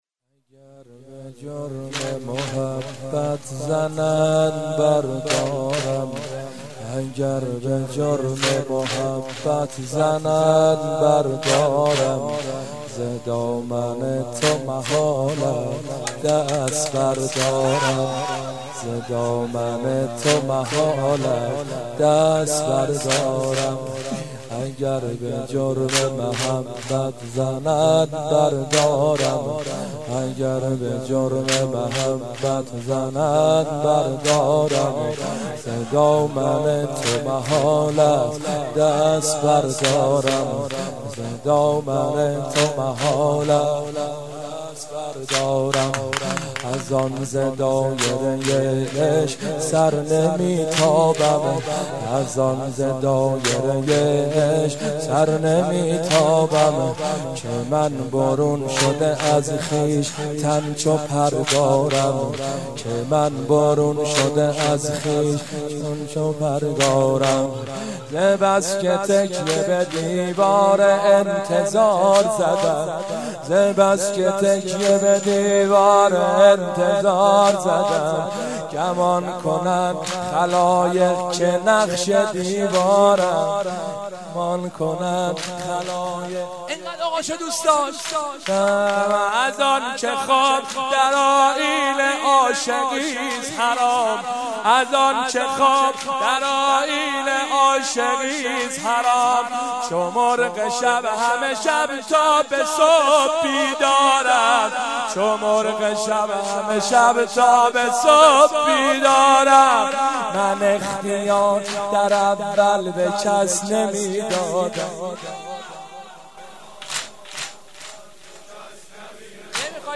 روضه
روضه سیدمجید بنی‌فاطمه